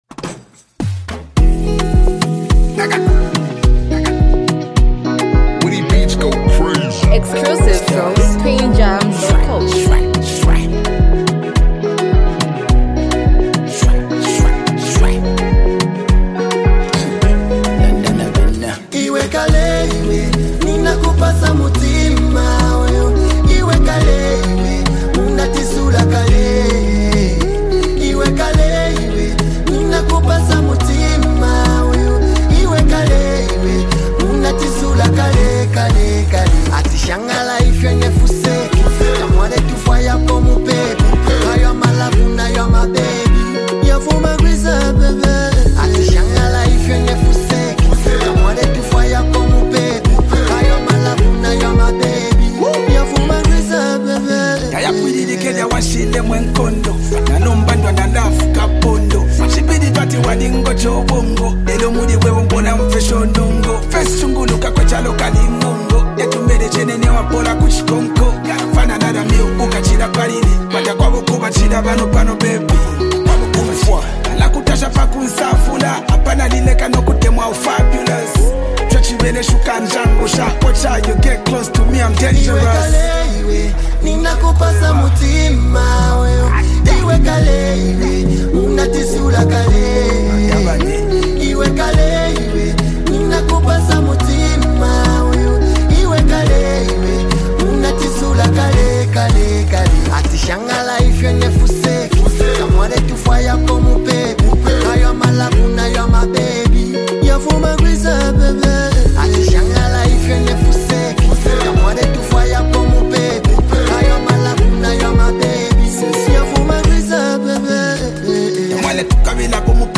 hype, street vibes, and a powerful hook
unique rap flow